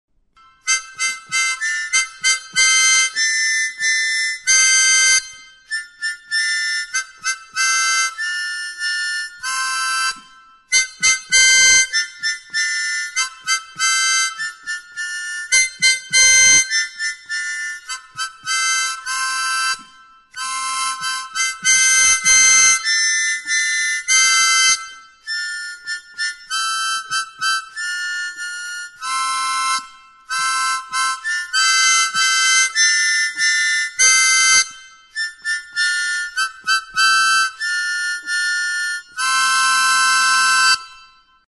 Music instrumentsAHO-SOINUA; HARMONIKA
Aerophones -> Reeds -> Single Free
Recorded with this music instrument.
Harmonika txikia da, 10 mihikoa.